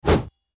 attack_both_wp_3.mp3